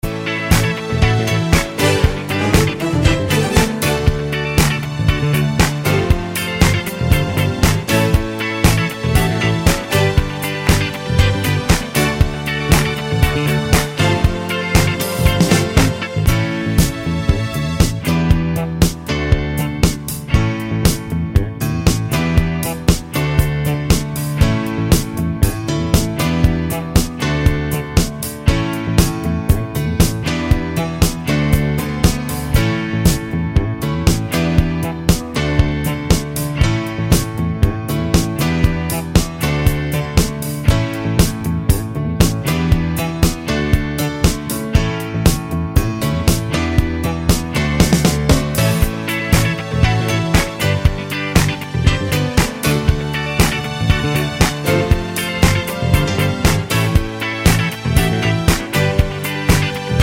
no Backing Vocals Disco 3:40 Buy £1.50